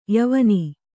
♪ yavani